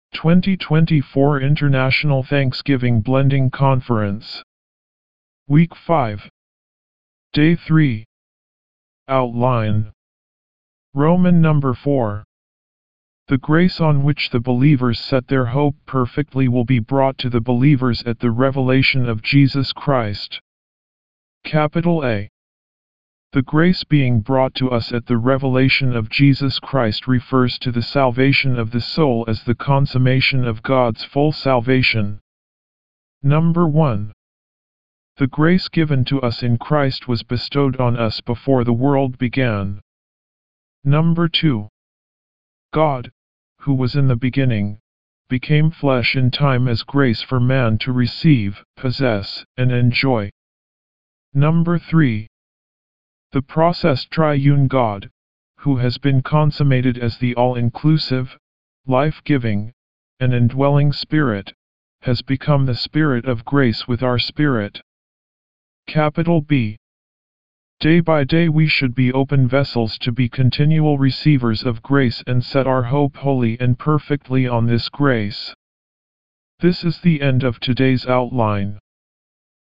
D3 English Rcite：